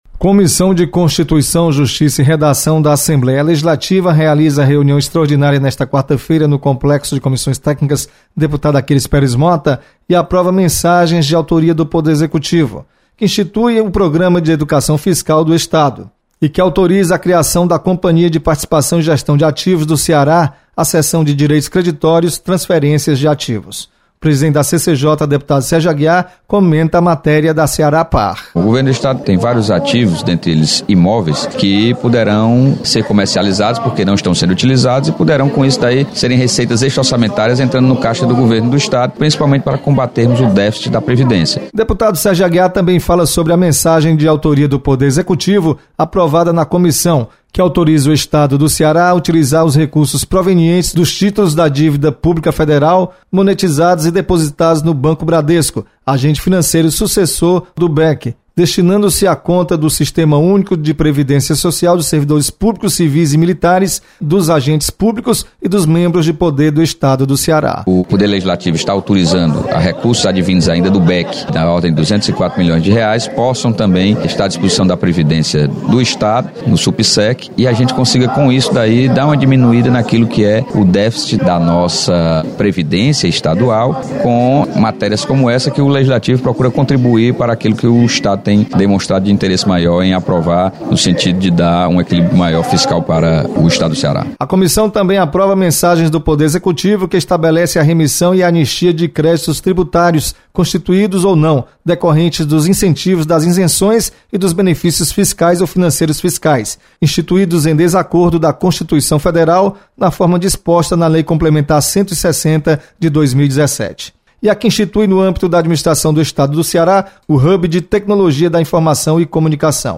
Comissão de Constituição, Justiça e Redação da Assembleia Legislativa realiza reunião nesta quarta-feira. Repórter